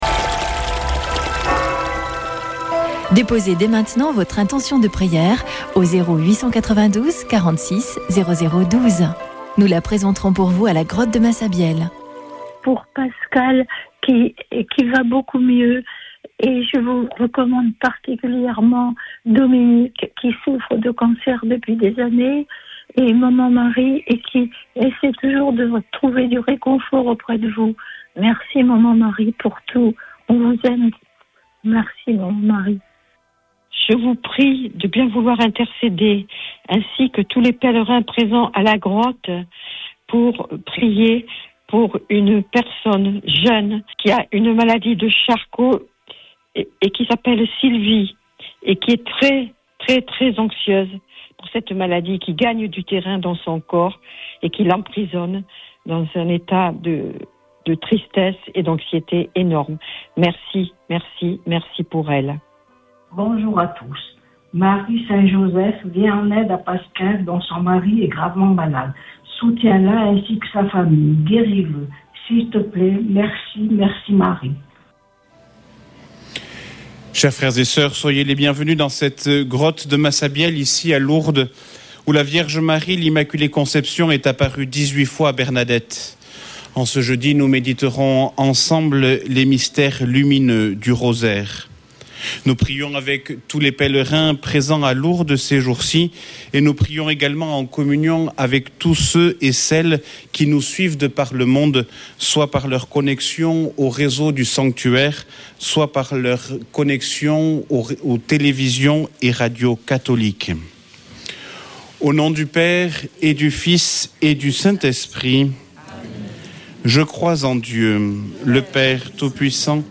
Chapelet de Lourdes du 22 janv.